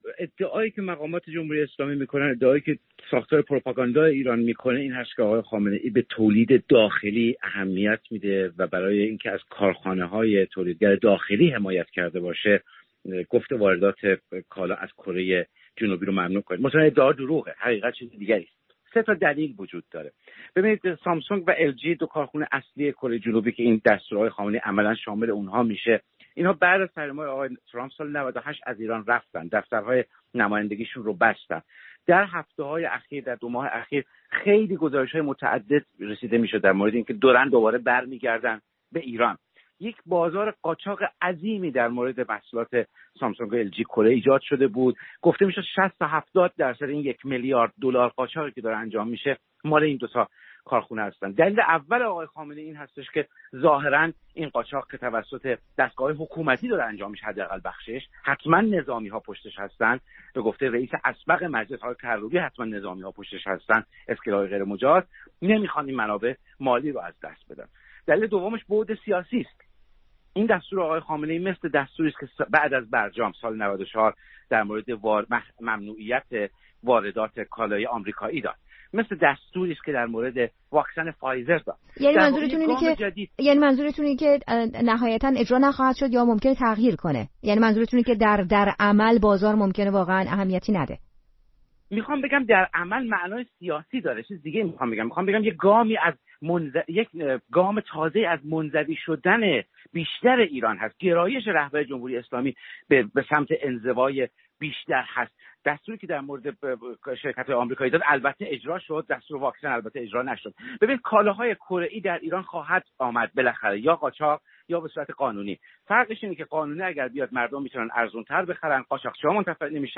روزنامه‌نگار و تحلیلگر ساکن آلمان